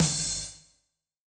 KIN Snare.wav